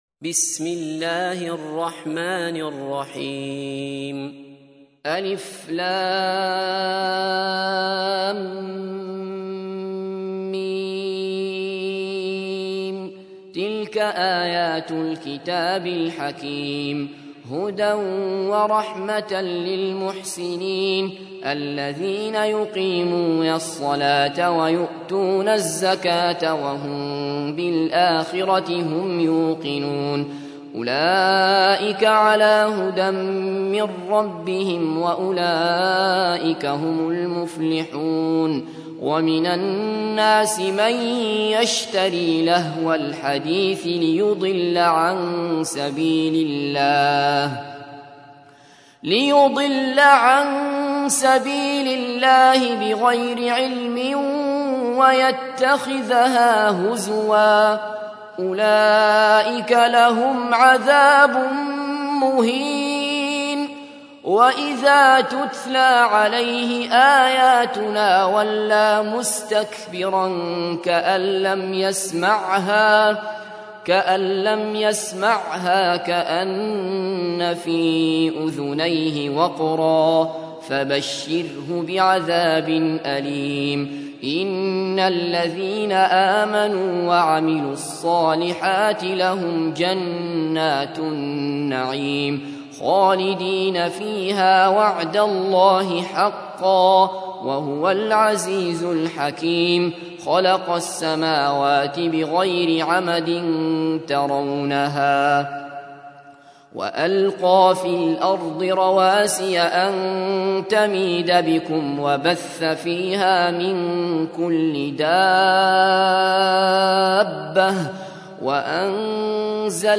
تحميل : 31. سورة لقمان / القارئ عبد الله بصفر / القرآن الكريم / موقع يا حسين